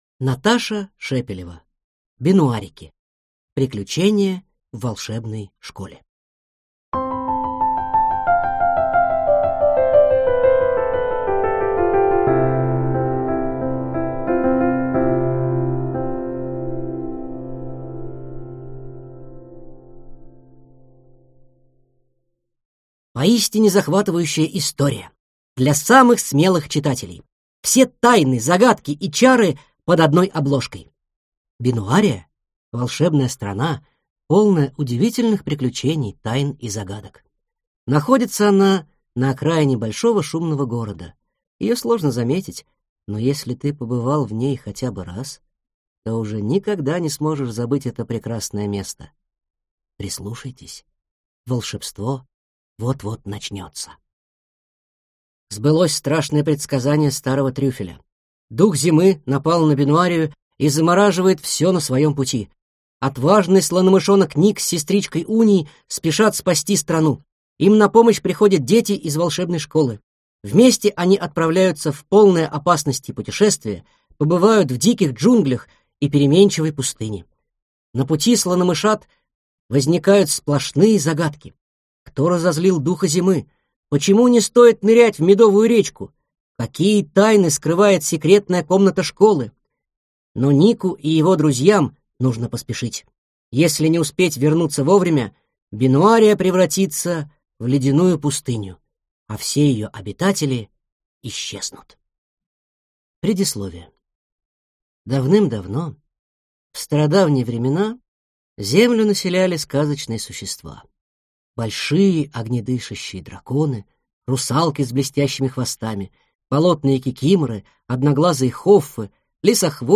Аудиокнига Бенуарики. Приключения в волшебной школе | Библиотека аудиокниг